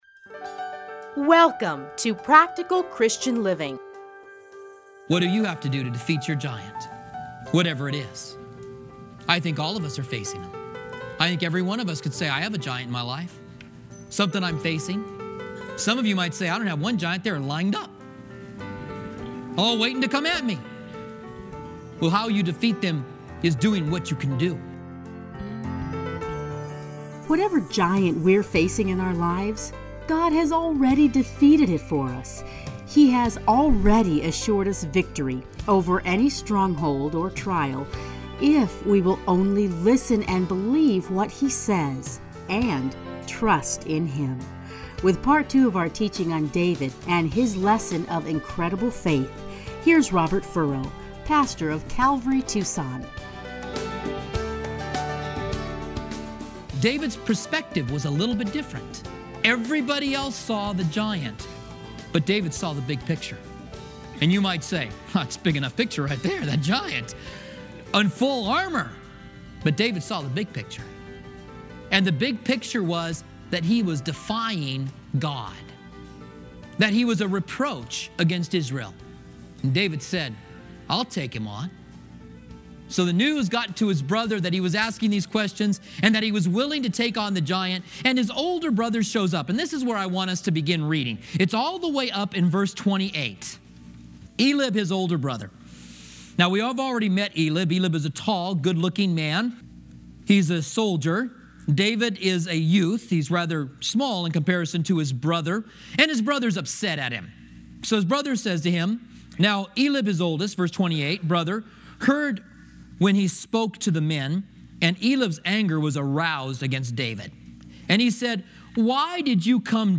radio programs